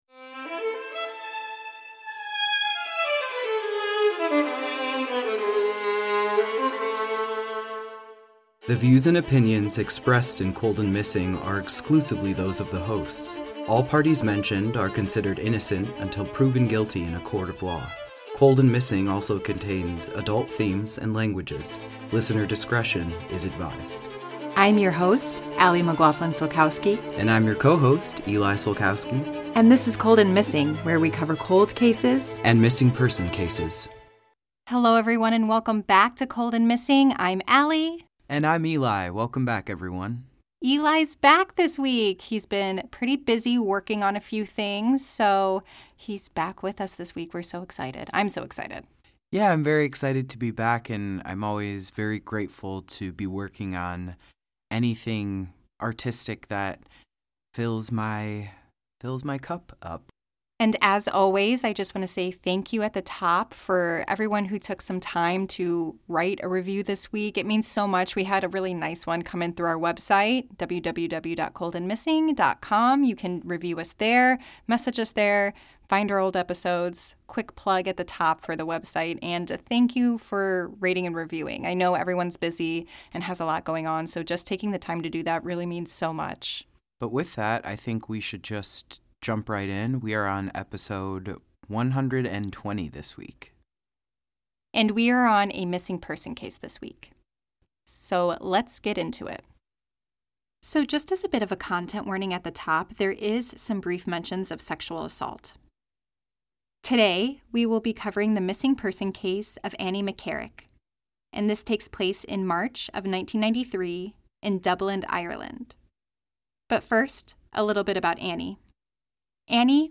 a husband and wife duo- will bring you either a Cold Case or a unresolved missing person.